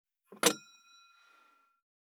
199,テーブル等に物を置く,食器,グラス,コップ,工具,小物,雑貨,コトン,トン,ゴト,
効果音物を置く